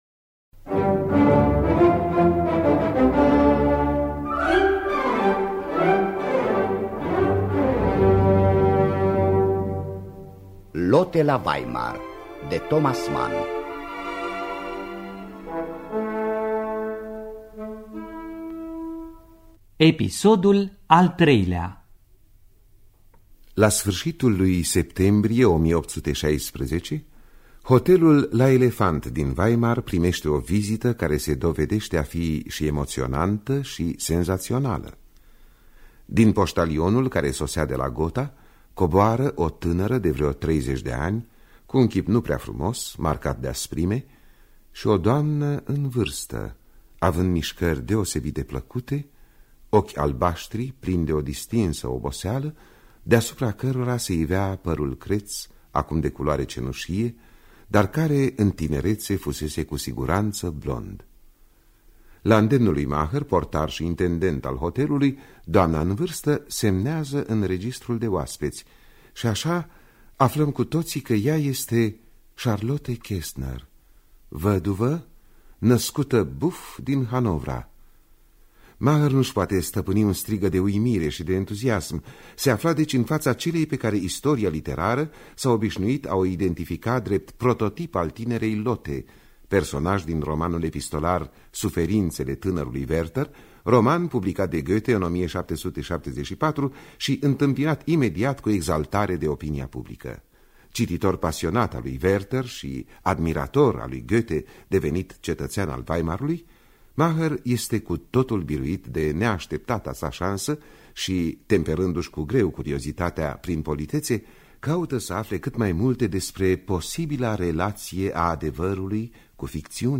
Dramatizarea radiofonică de Antoaneta Tănăsescu.